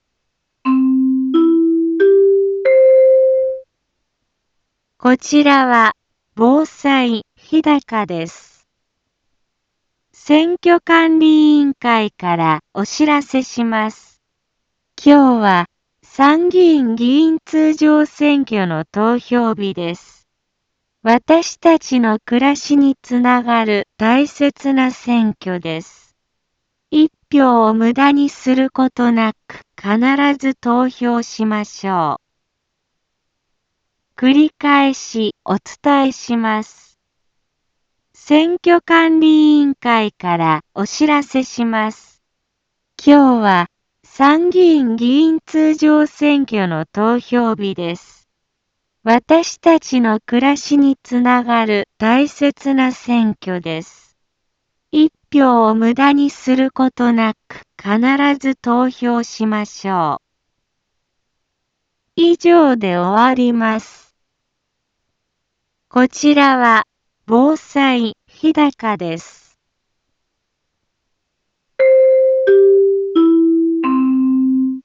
一般放送情報
BO-SAI navi Back Home 一般放送情報 音声放送 再生 一般放送情報 登録日時：2025-07-20 10:02:40 タイトル：参議院議員通常選挙投票棄権防止の呼びかけ インフォメーション： こちらは、防災日高です。